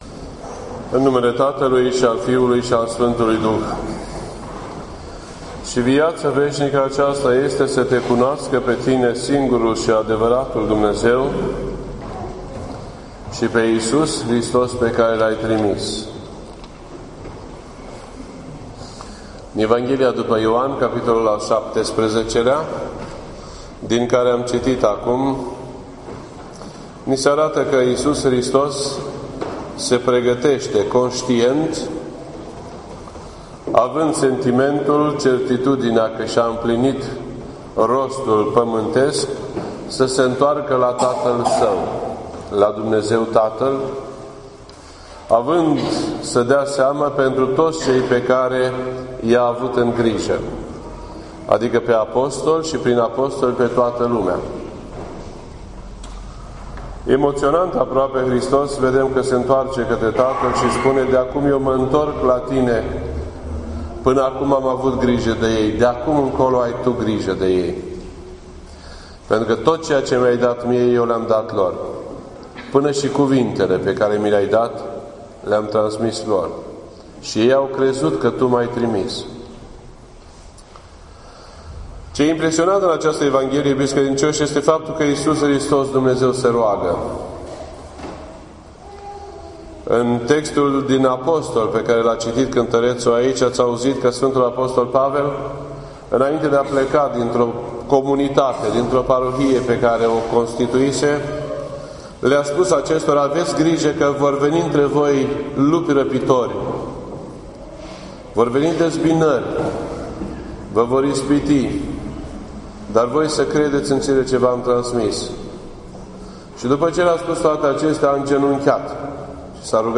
This entry was posted on Sunday, June 12th, 2016 at 10:40 AM and is filed under Predici ortodoxe in format audio.